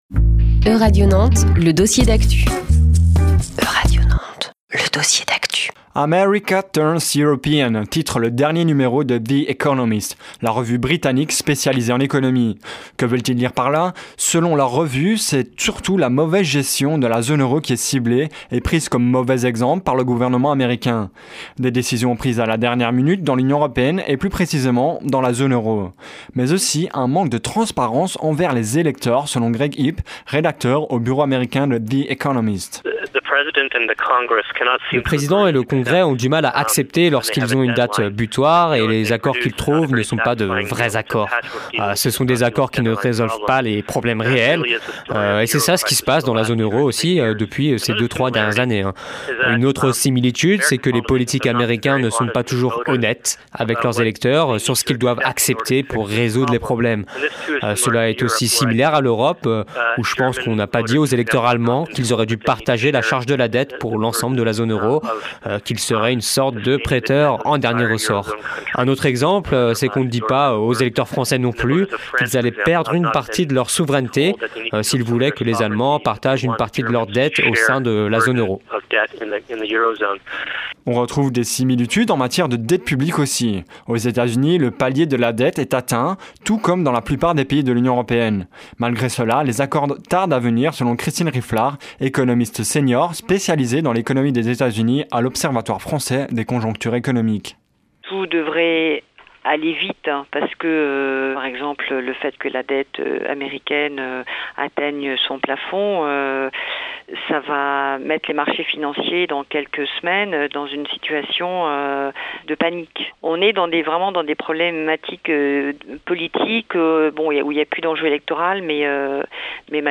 Conférence • Quelle Europe après le Brexit ?